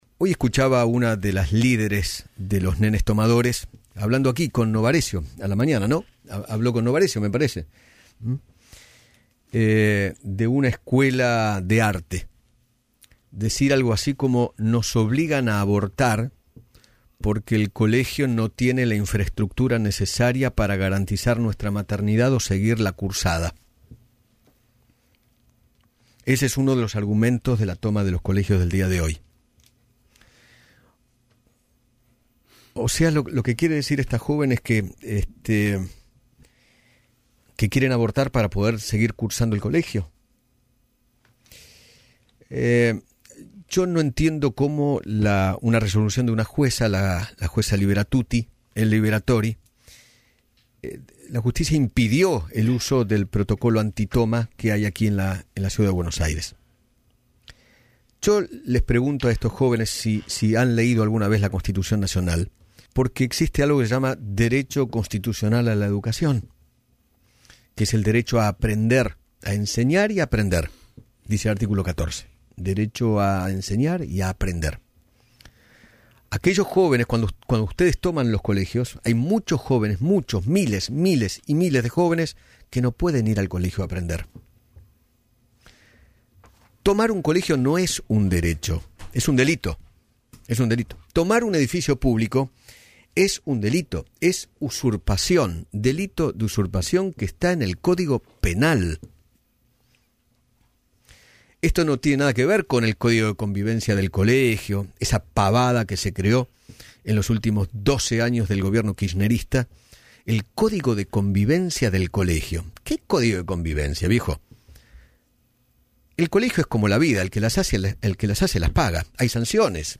Repasá las frases destacadas del editorial: